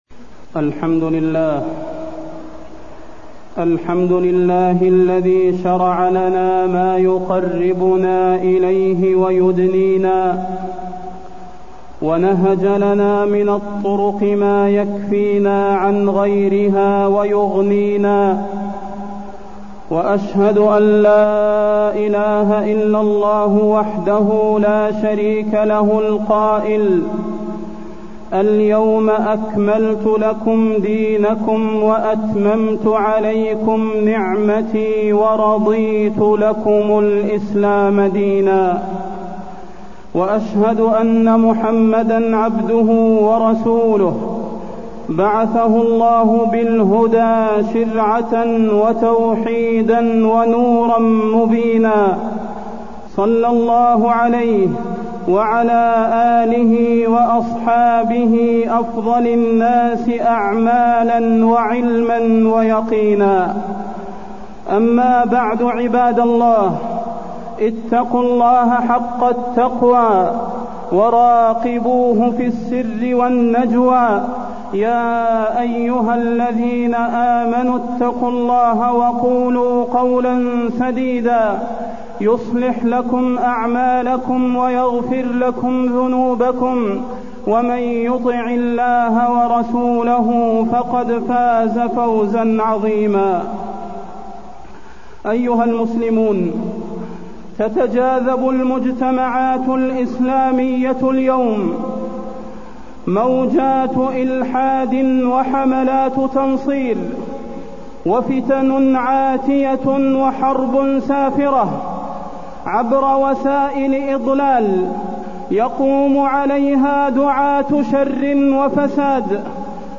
تاريخ النشر ٨ صفر ١٤٢١ هـ المكان: المسجد النبوي الشيخ: فضيلة الشيخ د. صلاح بن محمد البدير فضيلة الشيخ د. صلاح بن محمد البدير إمتثال أوامر الله